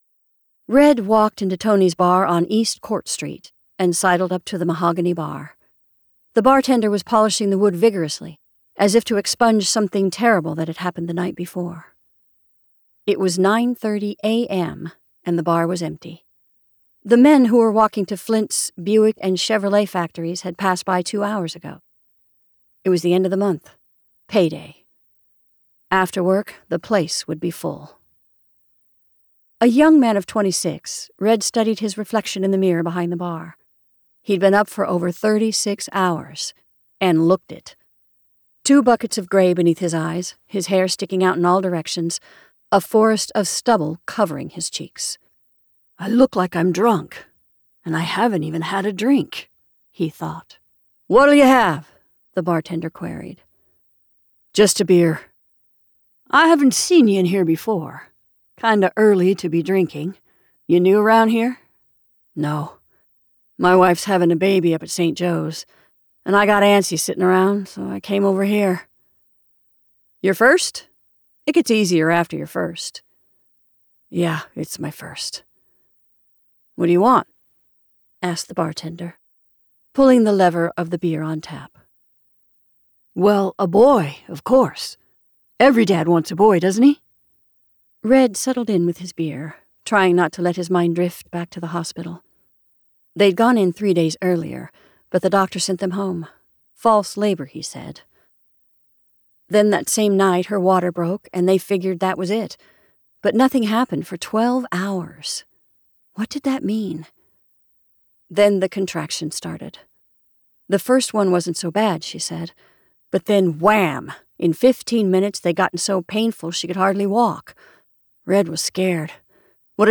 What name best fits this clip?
• Audiobook • 12 hrs, 48 mins